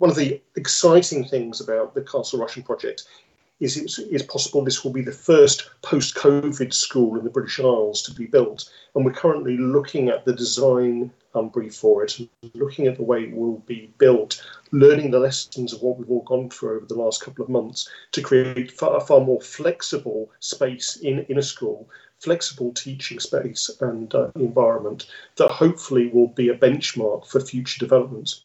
Dr Allinson says flexible teaching spaces could feature: